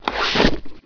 book_pickup.WAV